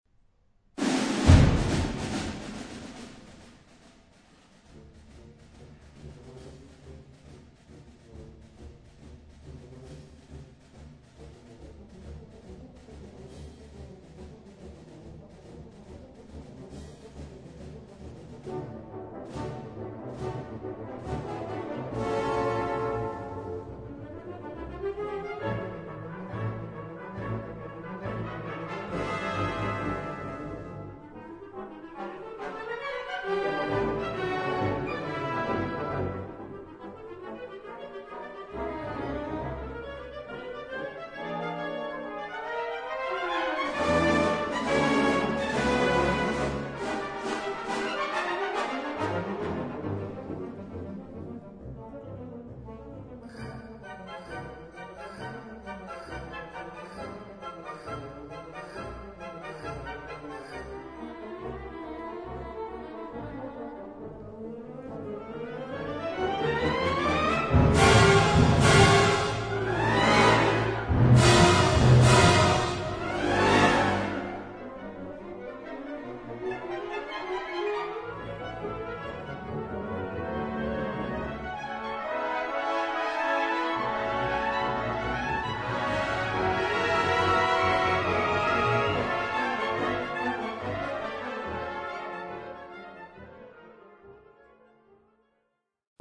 Live-registratie van het succesvolle concert
baritone